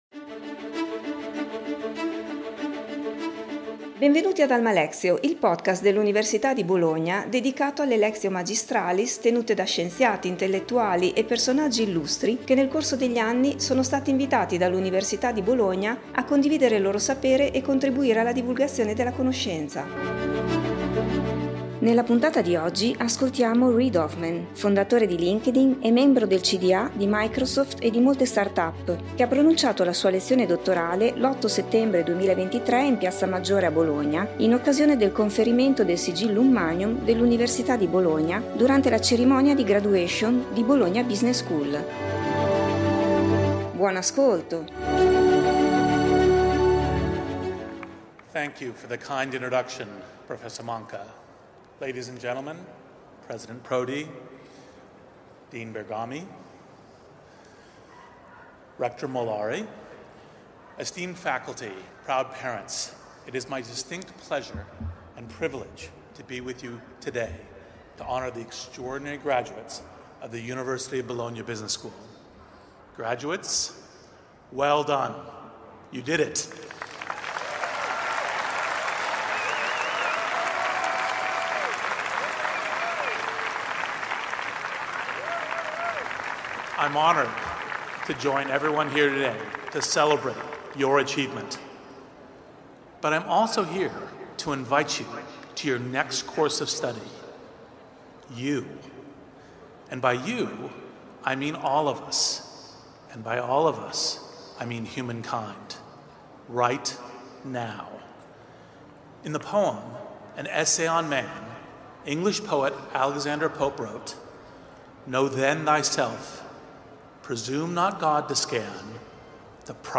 Reid Hoffman, fondatore di Linkedin e membro del CdA di Microsoft e di molte start up, ha pronunciato la sua lezione dottorale l’8 settembre 2023 in piazza Maggiore a Bologna in occasione del conferimento del Sigillum Magnum dell'Università di Bologna durante la cerimonia di Graduation di Bologna Business School.